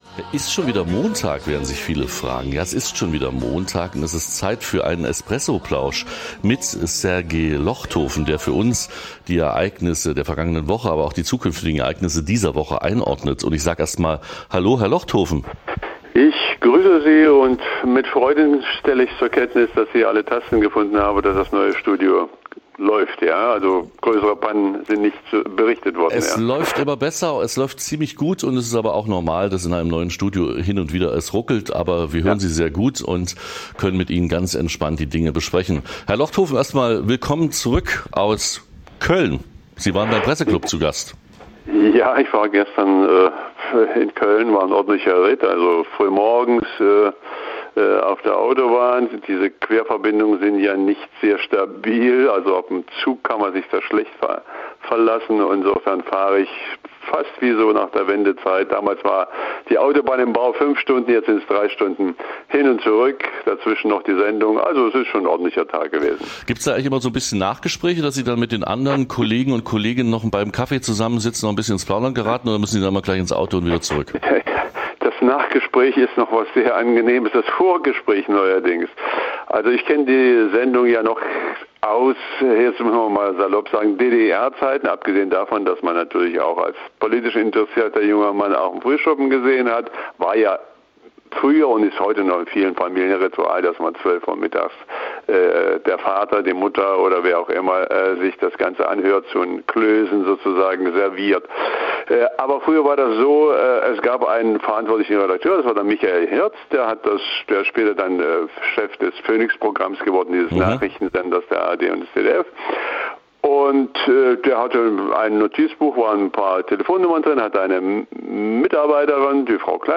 > Download Plauderei �ber Medien, Malerei und Belomorkanal